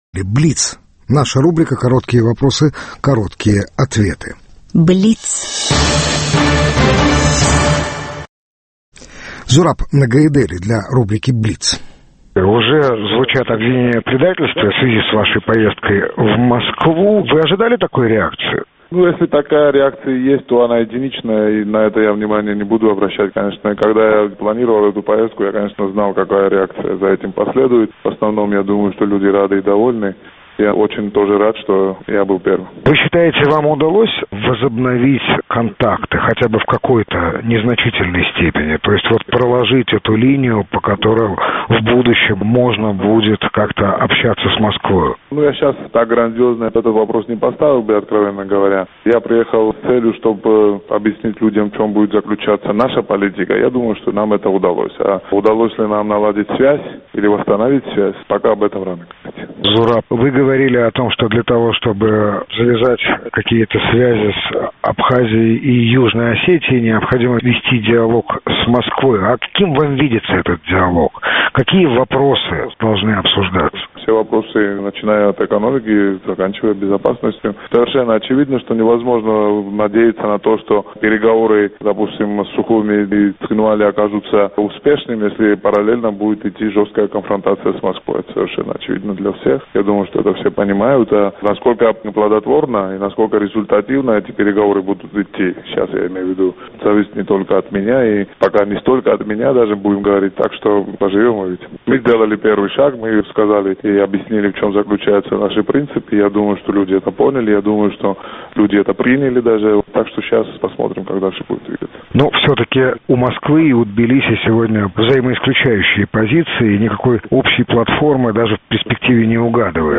Интервью Андрея Бабицкого с Зурабом Ногаидели